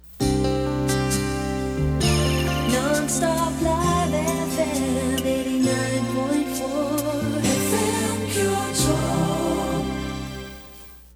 音源は全てステレオ録音です。
全体を通しての感想ですが、ジャズを基調としたとても贅沢な楽曲となっています。